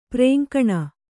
♪ prēŋkaṇa